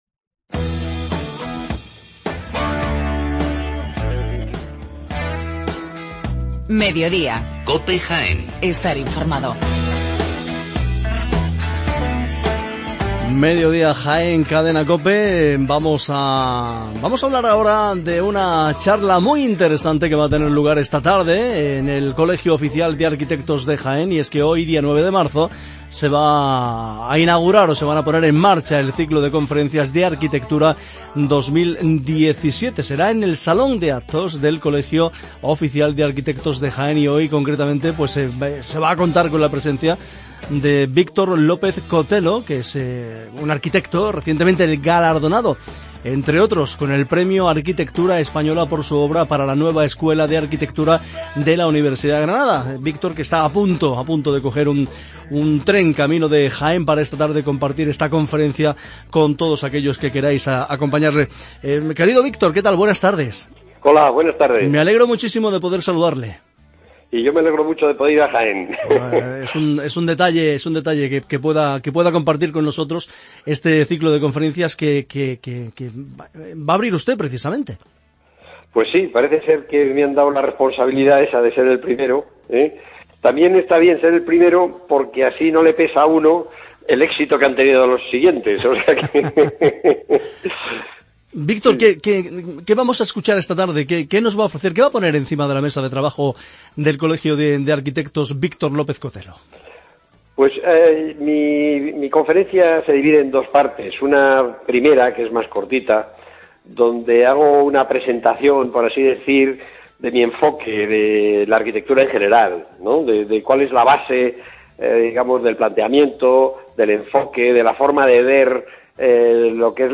Entrevista a Victor López Cotelo